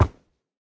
wood5.ogg